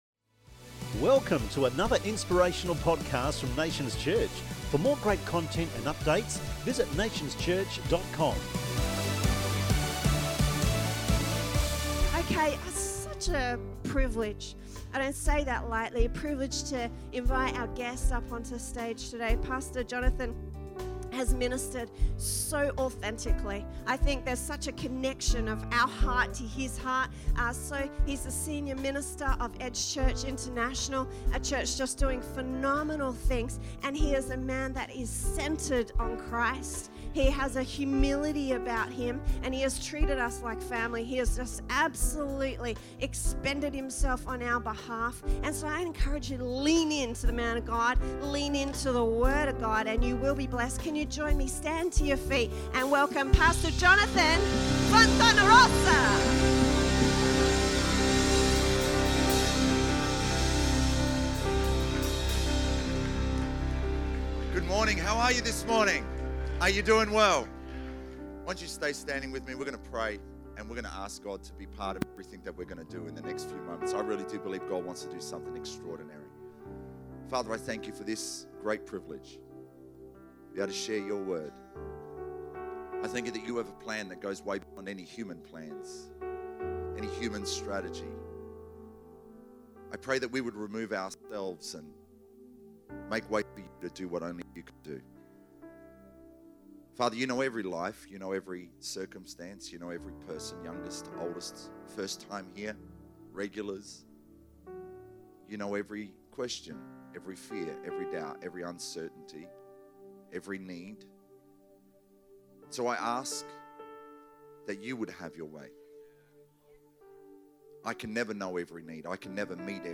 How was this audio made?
Healing Service